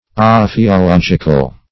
Search Result for " ophiological" : The Collaborative International Dictionary of English v.0.48: Ophiologic \O`phi*o*log"ic\, Ophiological \O`phi*o*log"ic*al\, a. Of or pertaining to ophiology.